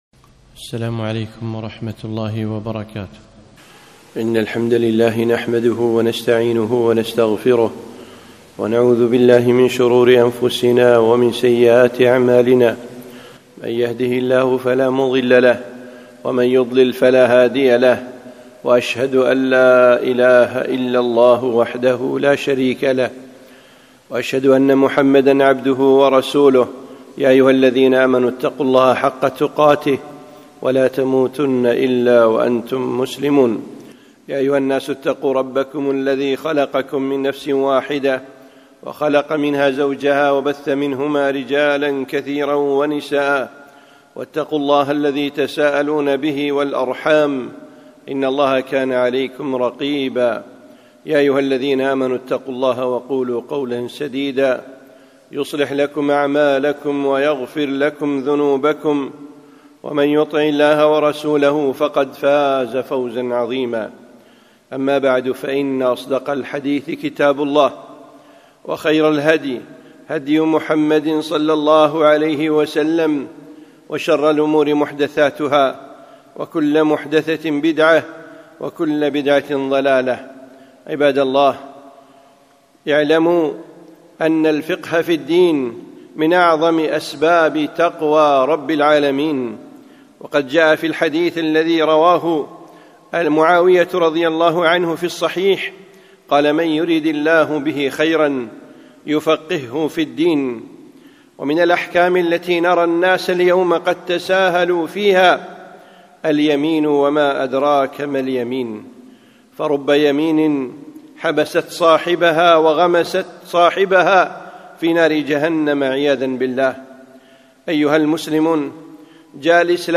خطبة - حفظ يمينك